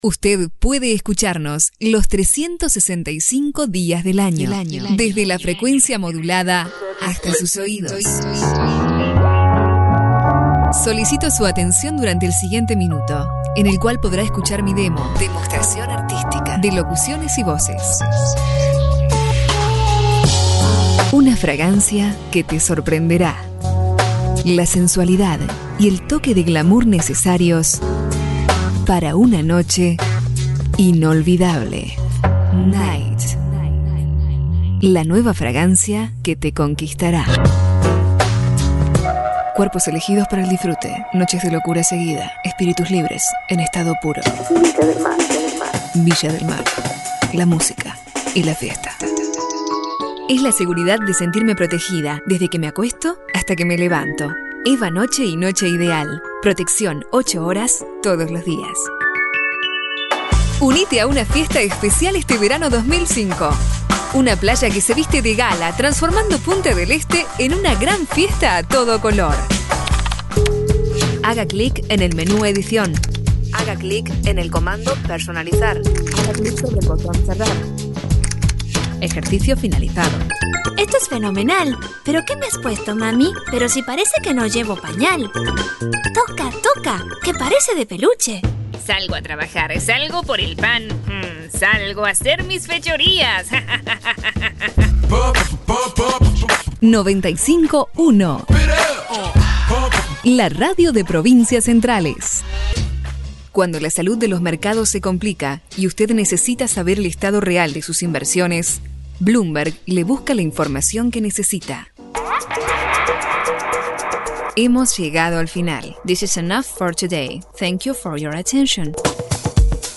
Sprecherin spanisch (Uruguay).
Sprechprobe: eLearning (Muttersprache):
female voice over talent spanish (uruguay)